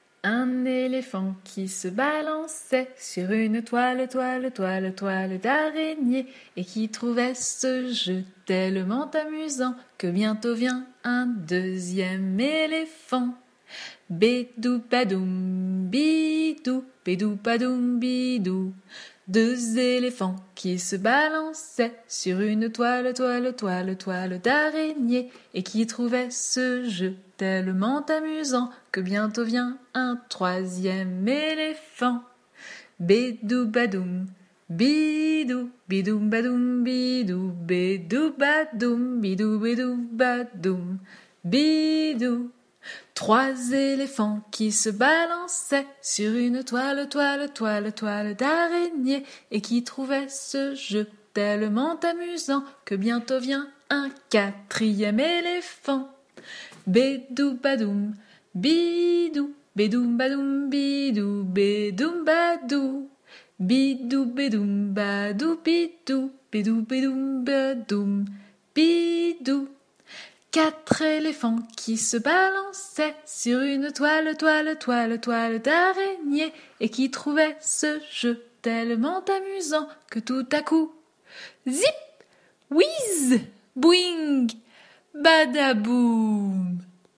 Comptines